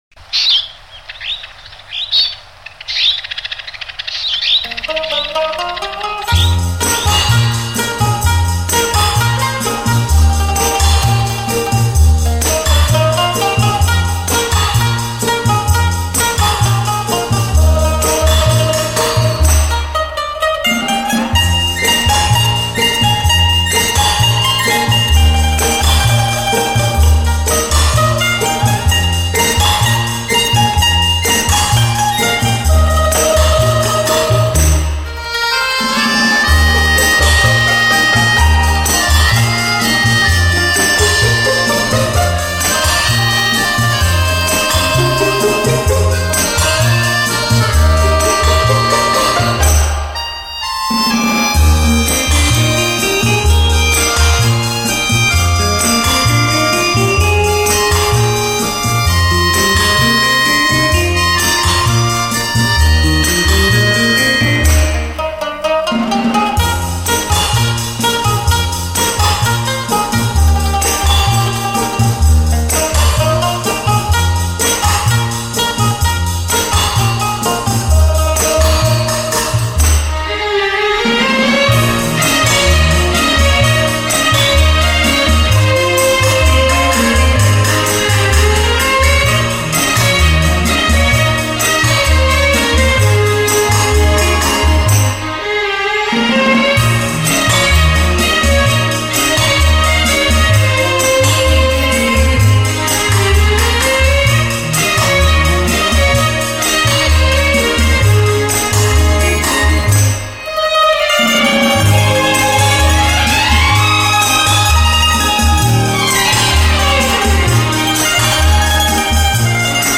唤起纯净恬适的微笑，本专辑收录了近百种鸟真实的鸣叫，融合优美的轻音乐，带你回归
为低音质MP3